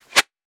weapon_bullet_flyby_05.wav